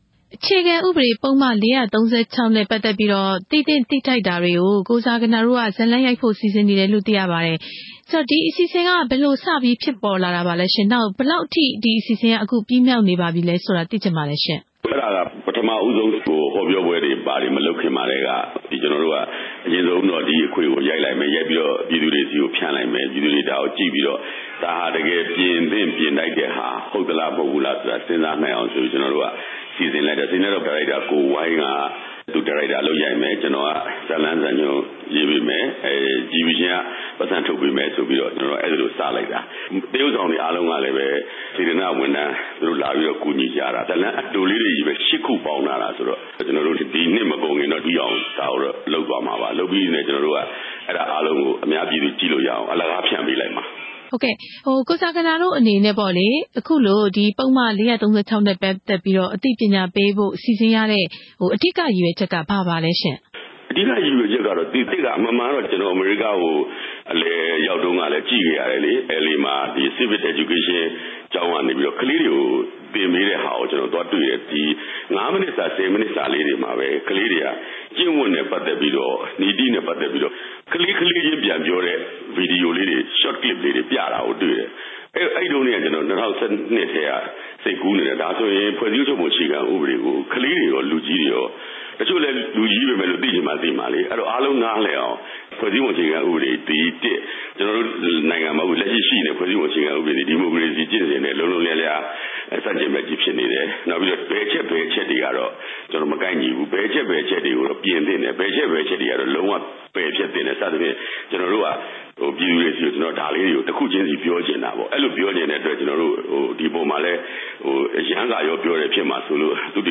ဦးသူရနဲ့ မေးမြန်းချက်